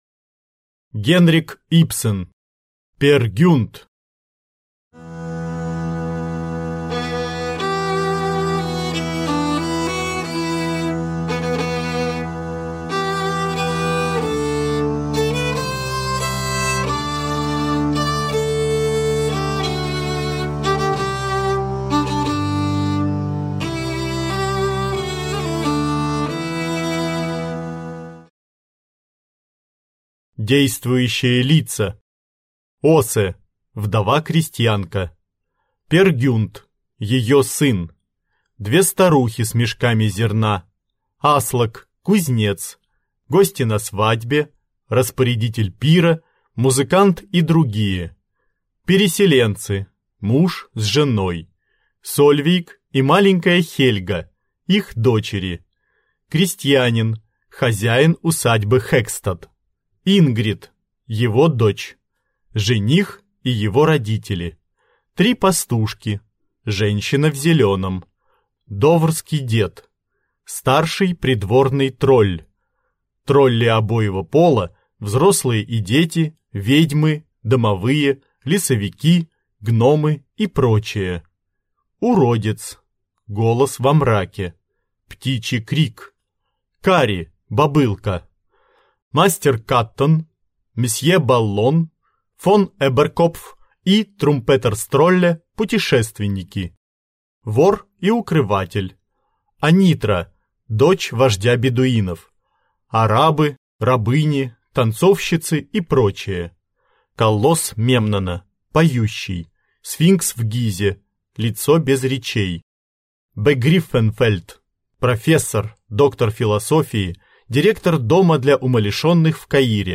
Аудиокнига Пер Гюнт | Библиотека аудиокниг